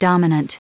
Prononciation du mot dominant en anglais (fichier audio)
Prononciation du mot : dominant